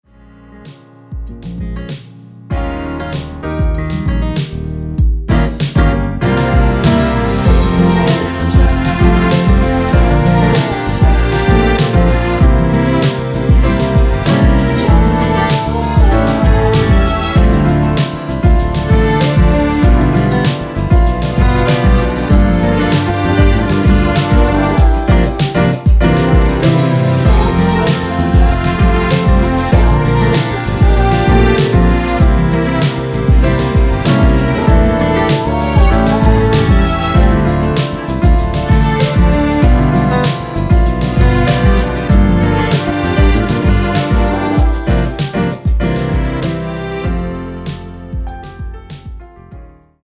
원키에서(-2)내린 코러스 포함된 MR입니다.
Ab
앞부분30초, 뒷부분30초씩 편집해서 올려 드리고 있습니다.